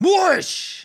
WOOSH.wav